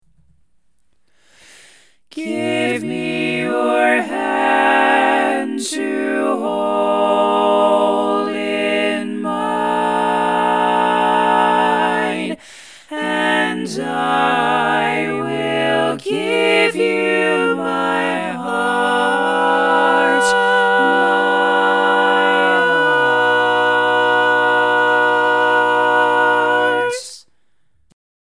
How many parts: 4
Type: Female Barbershop (incl. SAI, HI, etc)
Comments: Tracks in B.
All Parts mix:
Learning tracks sung by